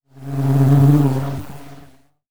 mixkit-big-bee-hard-flying-sound-42.wav